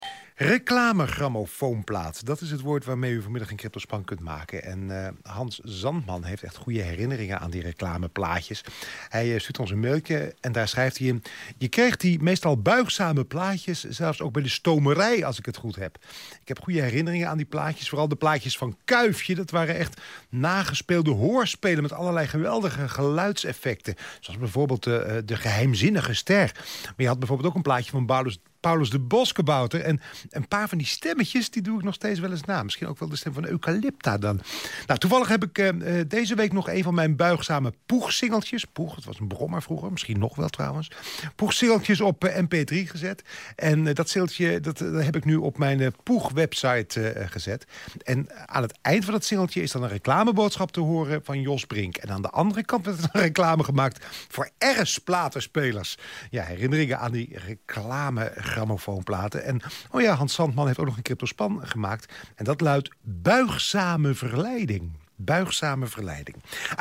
Ik heb erg goede herinneringen aan dit soort plaatjes, en ik wilde dit boek ook best wel winnen natuurlijk, dus ik stuurde een mailtje naar Radio 5, en oei ........ enige tijd later hoorde ik opeens mijn naam op de radio, en werd mijn mailtje voorgelezen, leuk!